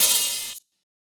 Open Hats
FM_OHH.wav